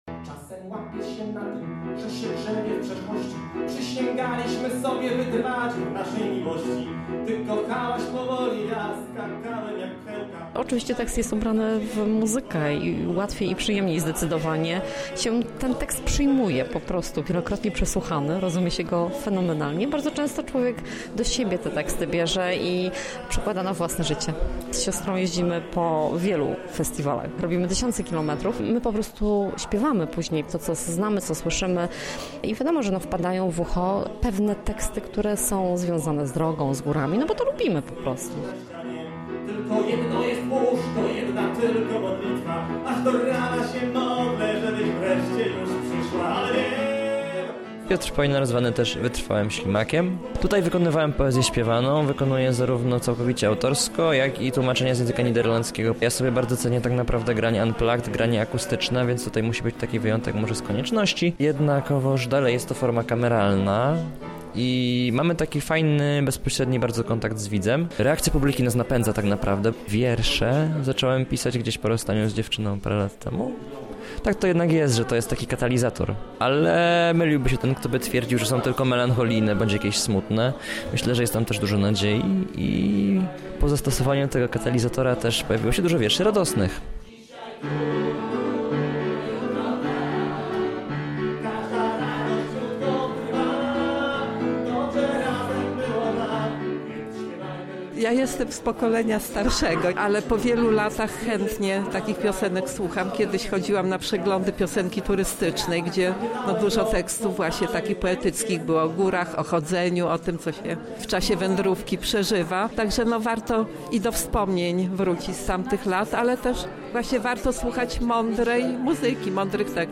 Gitara akustyczna, autorskie teksty i nieodzowna atmosfera Bieszczad królowały podczas 7. Lubelskiego Przeglądu Poetyckiego „Strojne w biel”.